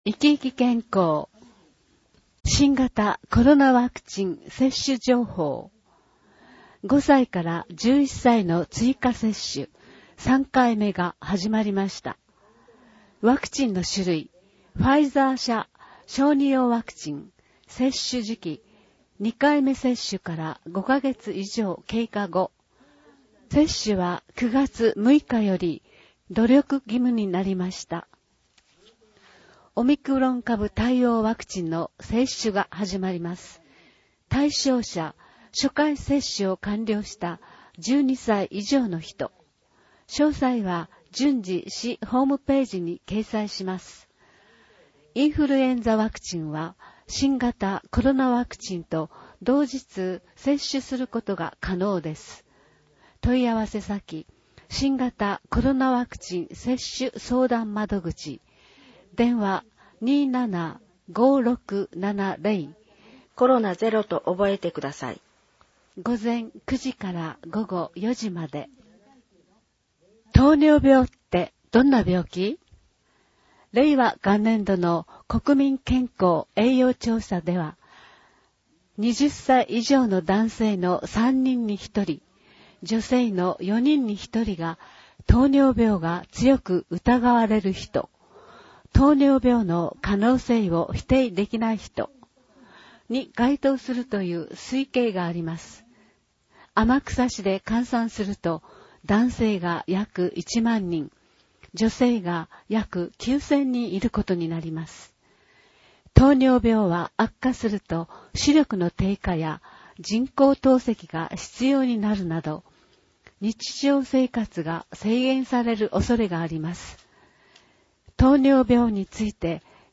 2022年10月号「市政だより天草」（第330号） 音声訳版